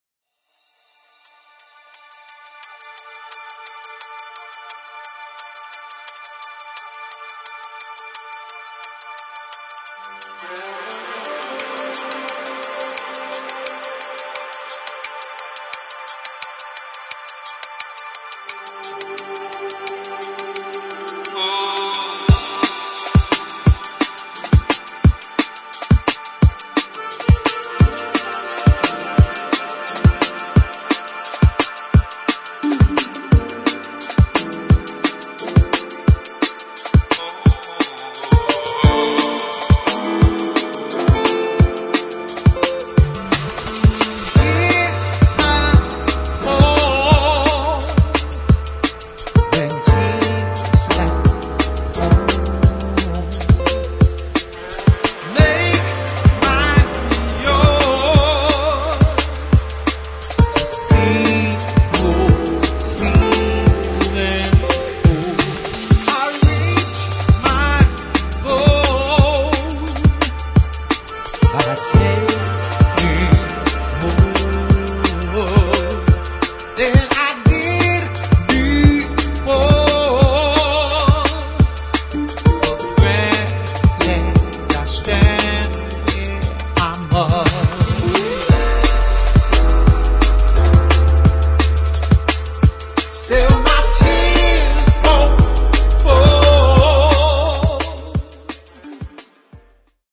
[ DRUM'N'BASS | JUNGLE ]